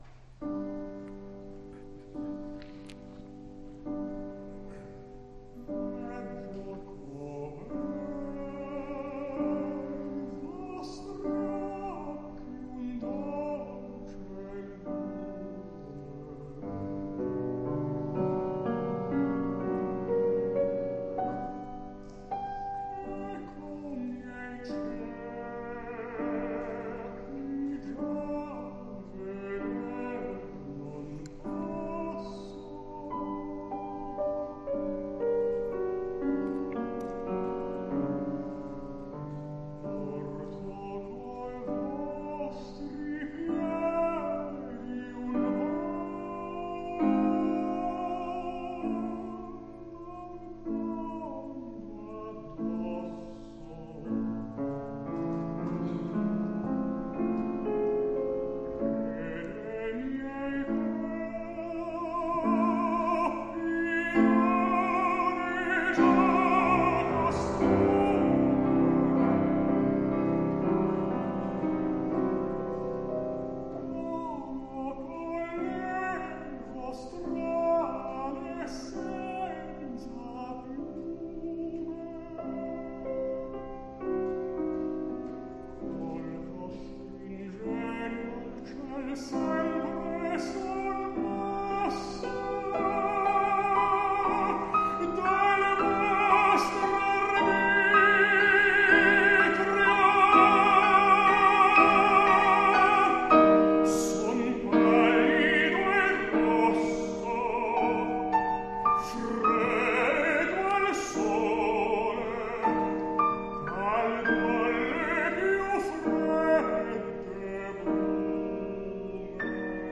Liederabend, München, Prinzregententheater, 22. Juli 2008
Sein baritonaler, hauchig-verschatteter Tenor erwies sich diesmal auch im Leisen sehr tragfähig.
(Veggio co'bei vostri..) legte er eine gewaltige Steigerung von leisen Tönen der Kopfstimme bis zu kraftvollem Forte hin, das in dieser Sternstunde kultivierter Liedinterpretation nie zum Selbstzweck herabsank, sondern stets das Geheimnis großer Liebe dieser anspruchsvollen Gesänge auslotete.